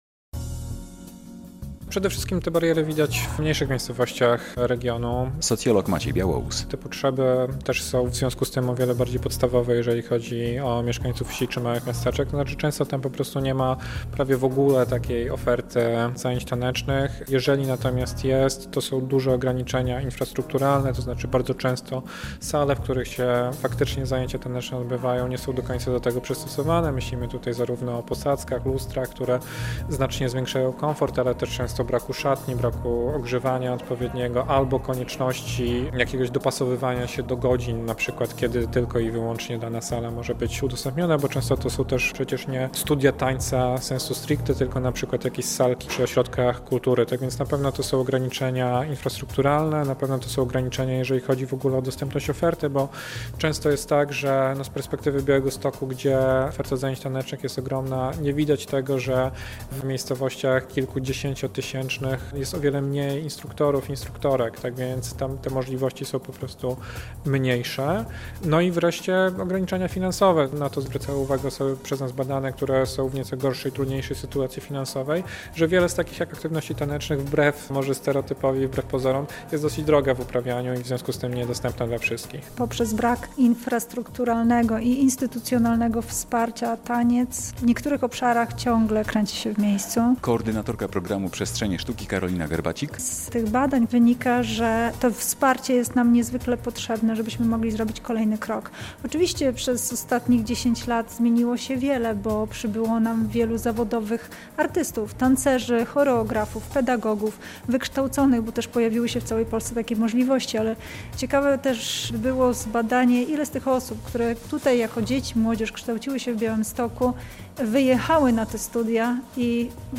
Artyści i naukowcy dyskutowali w Białymstoku